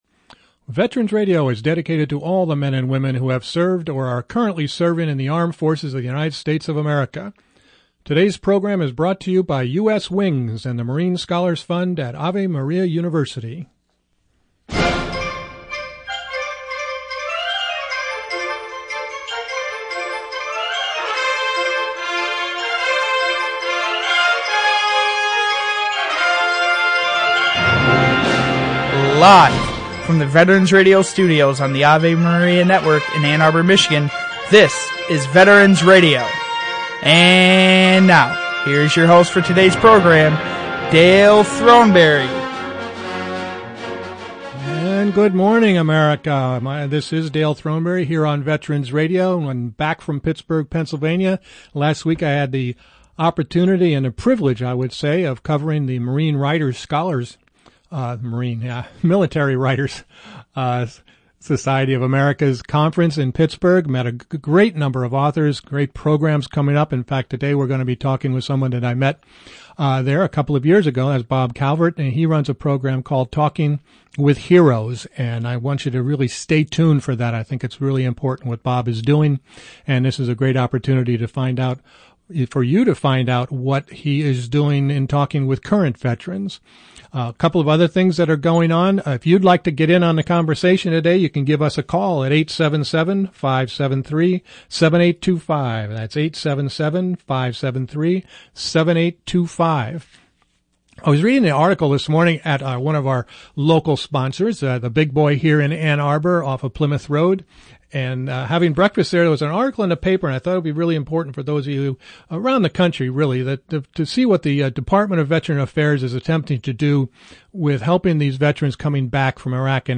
Broadcasting live from the Annual Military Writers Conference at the Pittsburgh Airport Marriott.